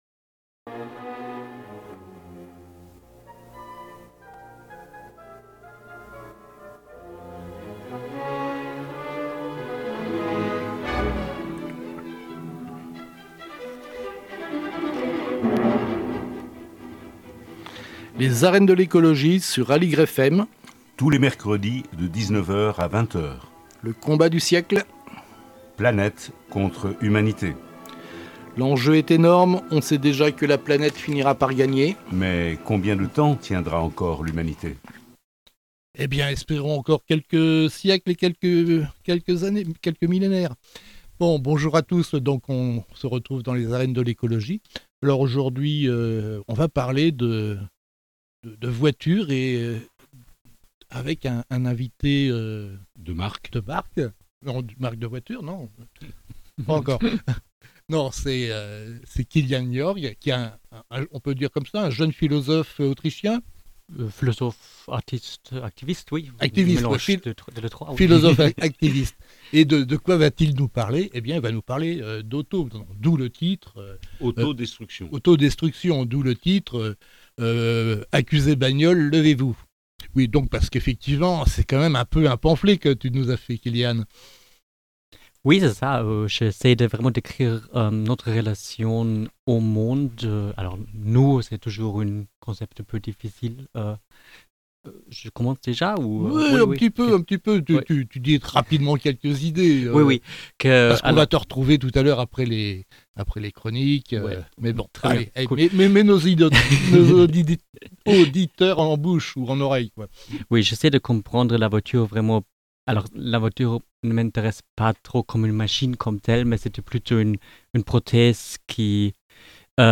Une conversation qui vous plongera dans l'impensé de la voiture.